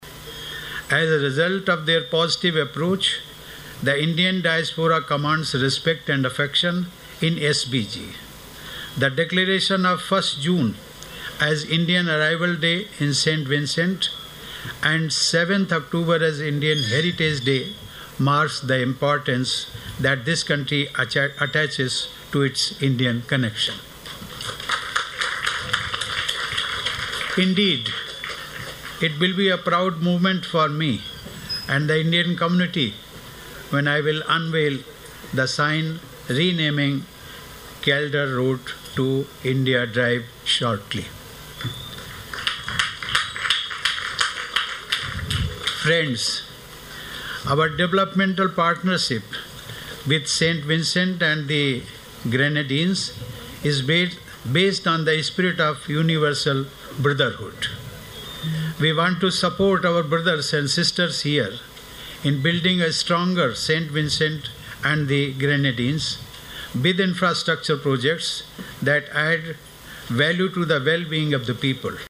The Prime Minister spoke on the issue, as he addressed a ceremony held here yesterday to mark the re-naming of a road in the Calder community to India Drive.
The ceremony was held to coincide with the official visit of the President of the Republic of India, His Excellency Ram Nath Kovind.